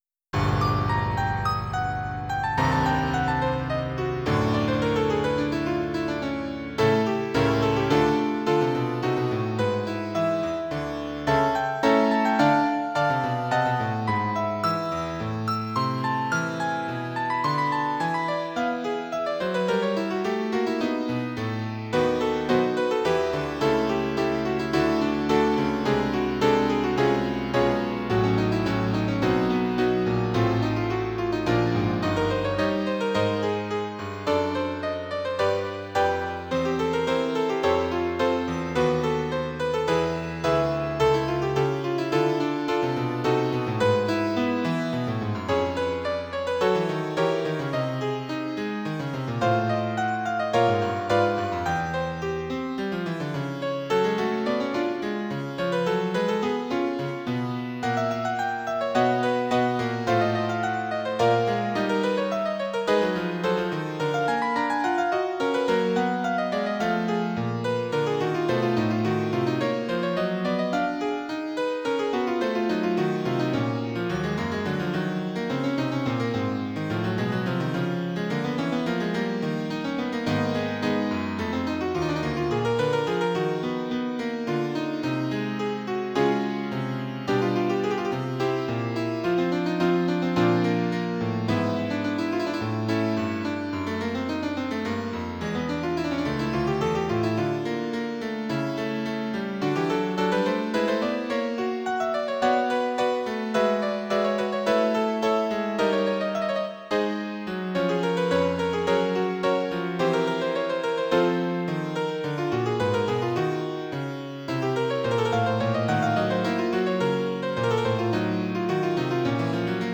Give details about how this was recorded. Most of these pieces I performed myself in public concerts. The sound quality is slightly better on the CD.